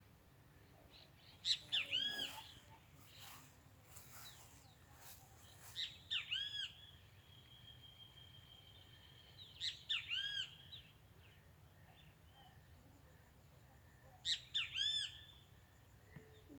Great Kiskadee (Pitangus sulphuratus)
Detailed location: Estancia La Reserva
Condition: Wild
Certainty: Observed, Recorded vocal